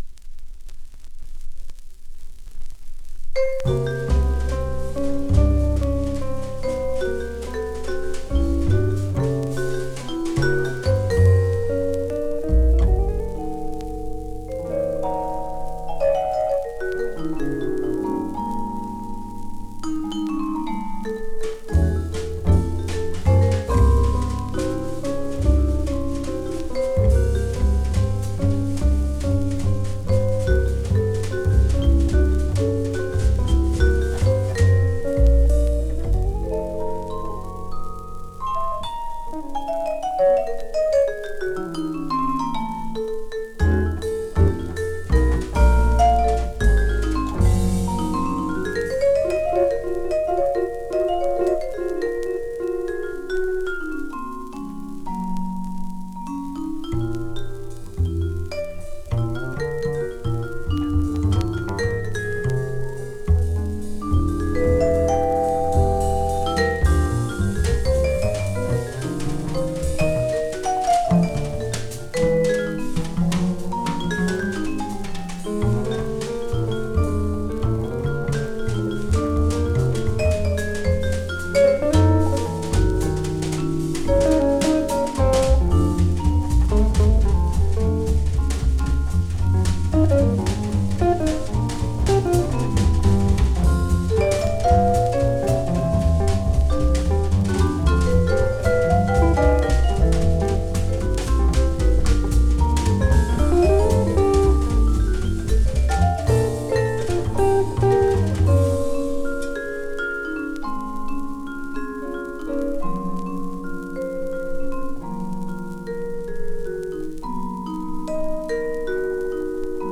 The vibes are a naturally cool-toned instrument